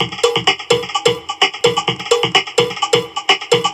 VEH1 Fx Loops 128 BPM
VEH1 FX Loop - 31.wav